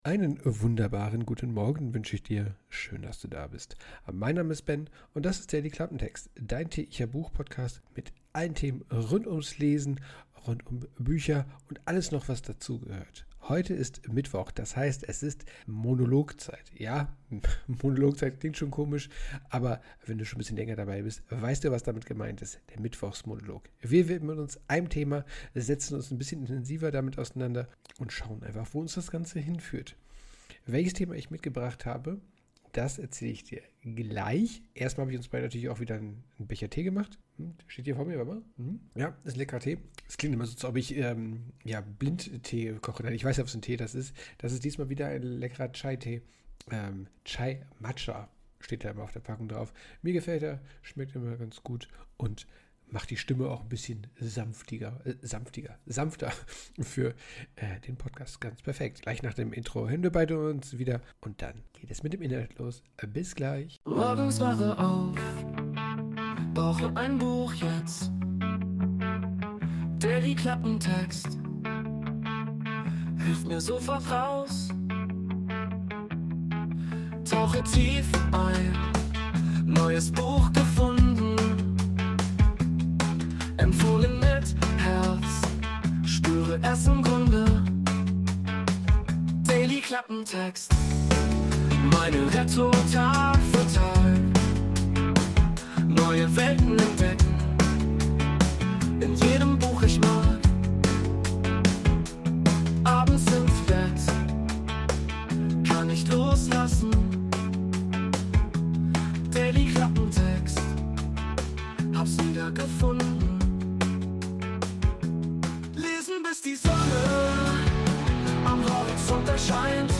Folge 160: Mittwochsmonolog: Warum ich Bücher abbreche? | Monolog ~ Dailyklappentext Podcast